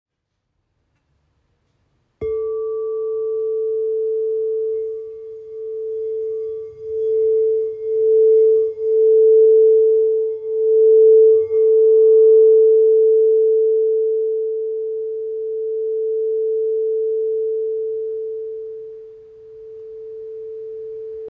Bol en cristal 8 pouces · Gamme A
Le choix de diamètres appropriés pour chaque fréquence leur confère une stabilité de résonance exceptionnelle.
Diamètre : 20cm
Note: La